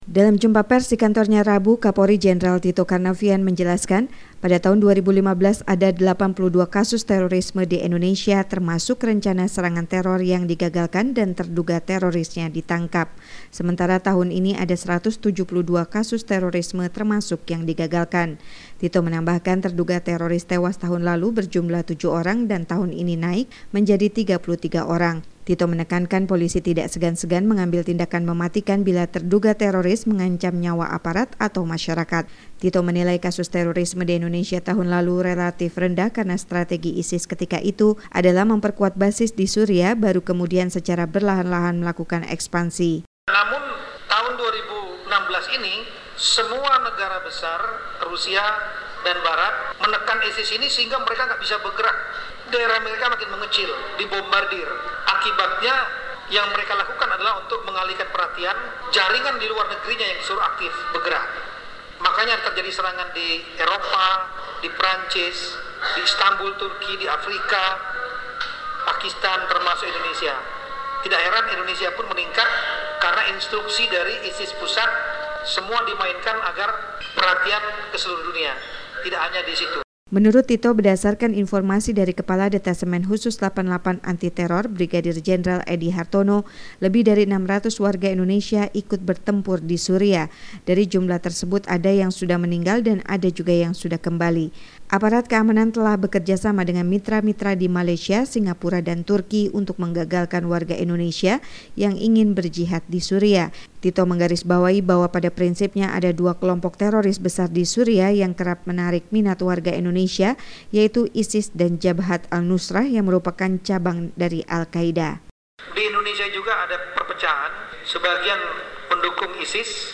melaporkannya dari Jakarta.